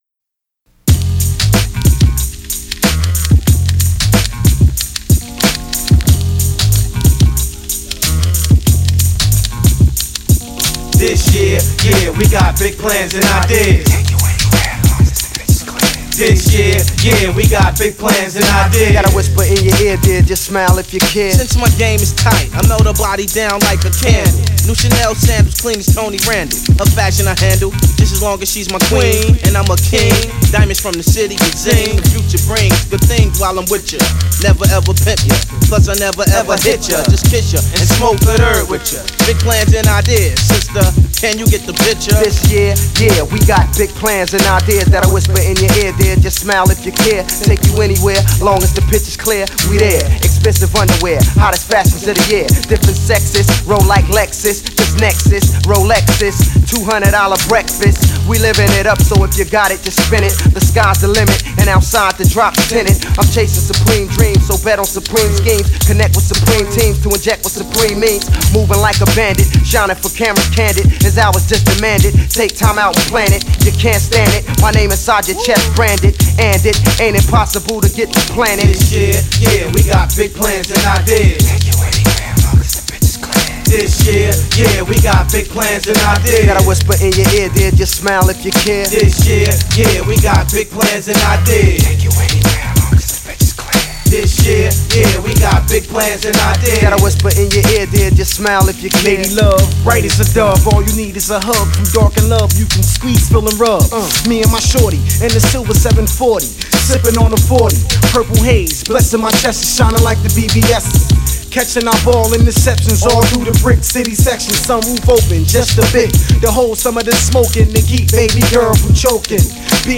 Rap Group
This Beat and the MC’s is dope. this sounds like right now!